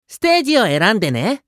男性
☆★☆★システム音声☆★☆★